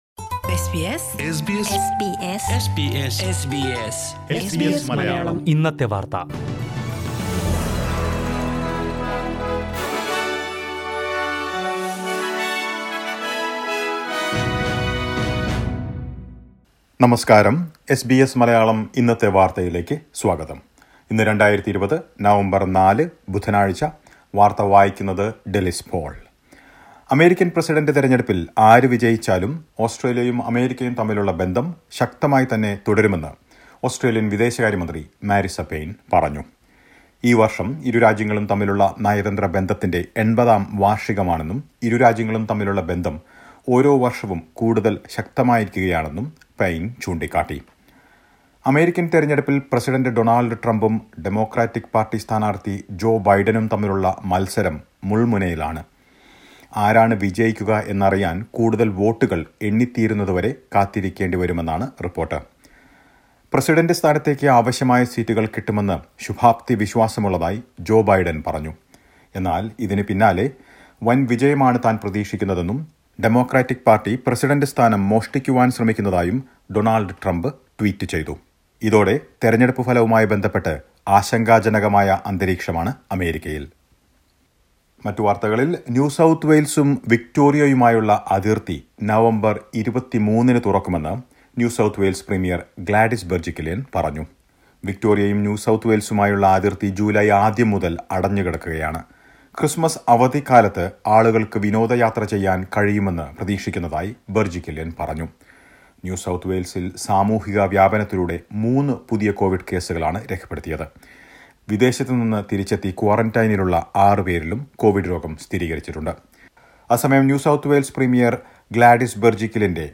Listen to the most important news from Australia…